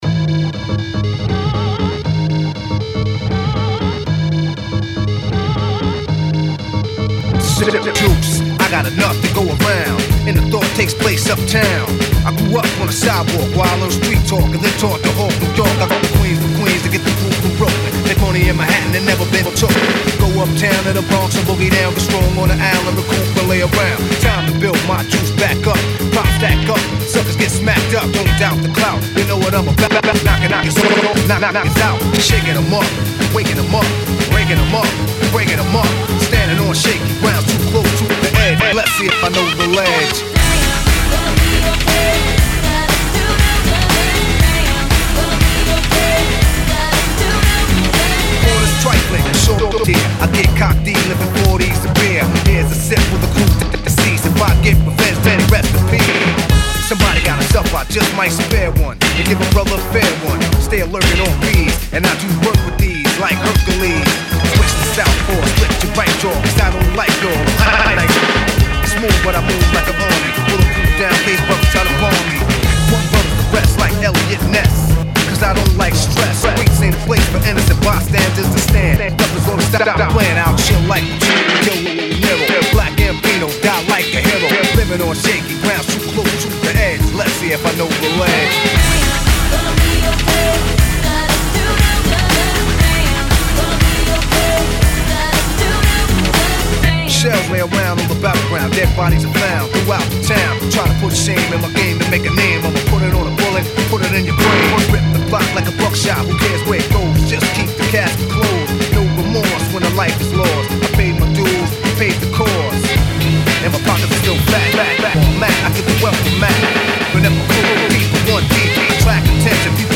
mix